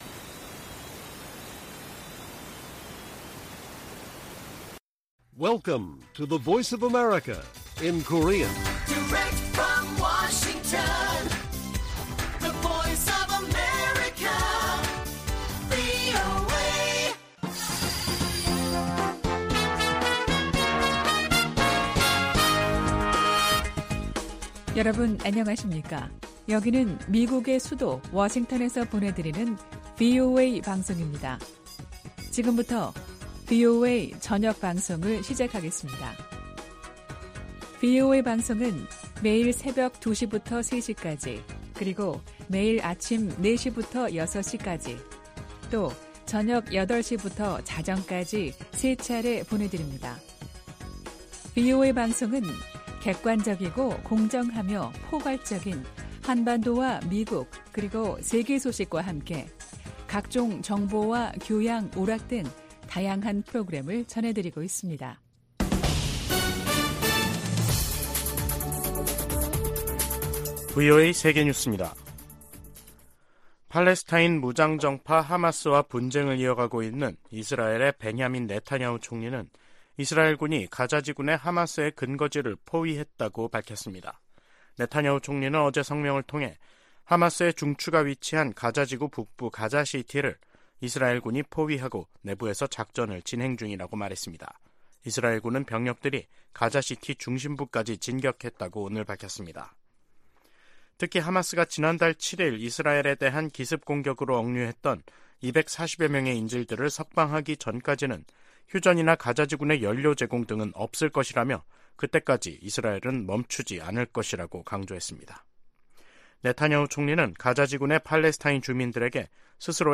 VOA 한국어 간판 뉴스 프로그램 '뉴스 투데이', 2023년 11월 8일 1부 방송입니다.